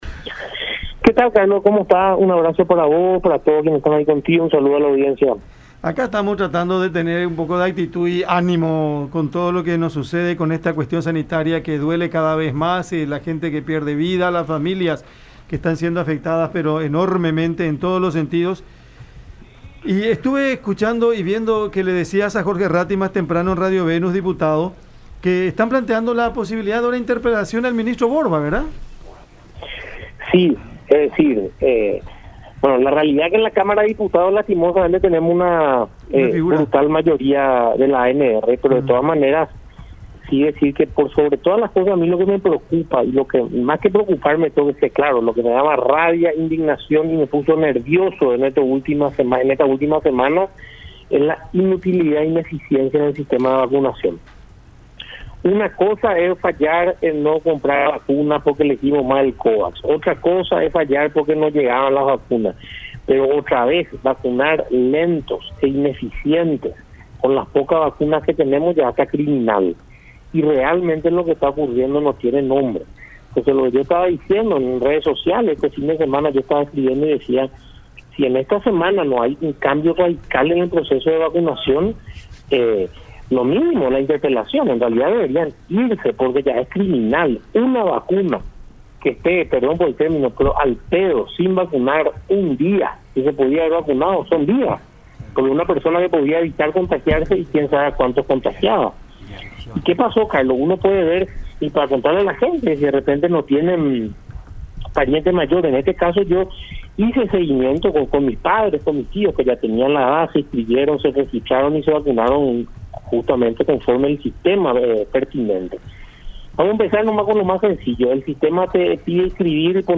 “Lo que me preocupa, me da rabia e indignación es la ineficiencia del sistema de vacunación. Se vacuna de forma lenta e ineficiente y encima con poca cantidad. Eso es lo que indigna. Planteé el tema de la interpelación al ministro de Salud justamente por estas irregularidades”, dijo Villarejo en charla con La Unión.